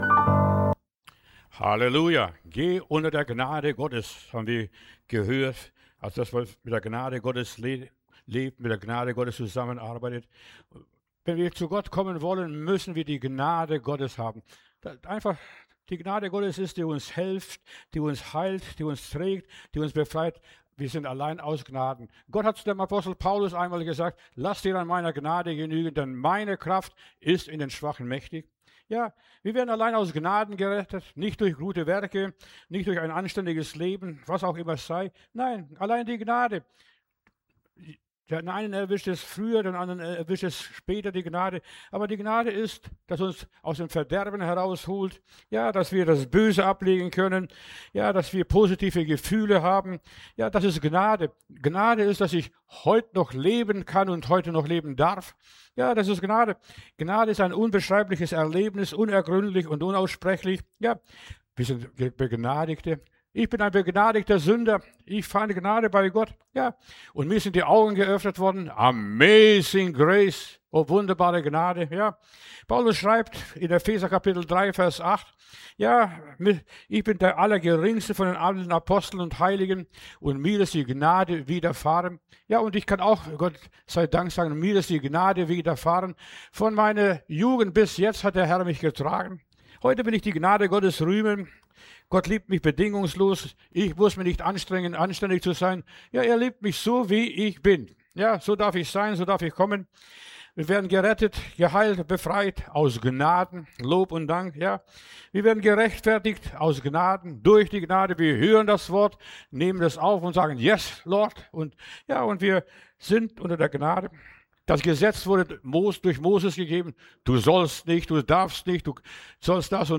Predigt herunterladen: Audio 2026-01-14 Die Gnade Video Die Gnade